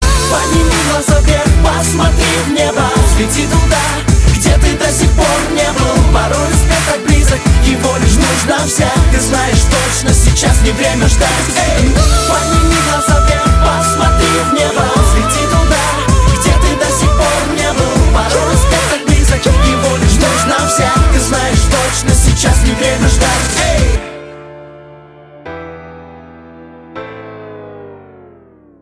• Качество: 192, Stereo
мотивационные